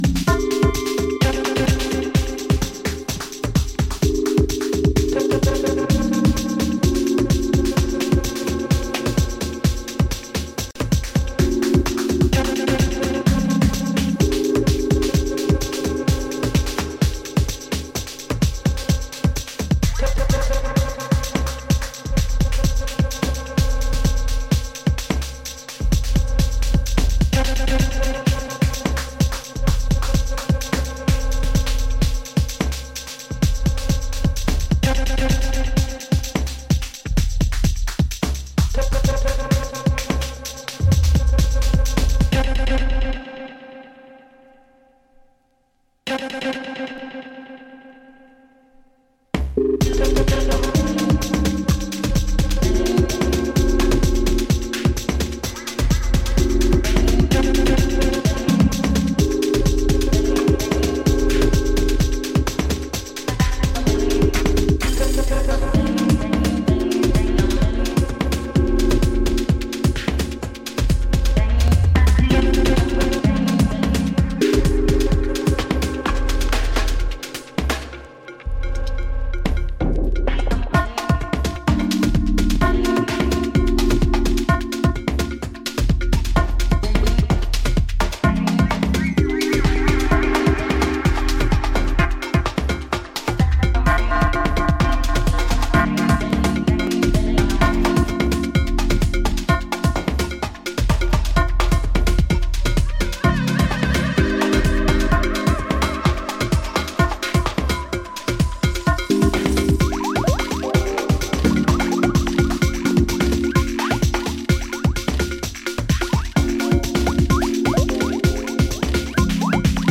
ドリーミーな旋律と立体的でパーカッシヴな音響が心地良い128BPMのブロークンビーツ・テクノ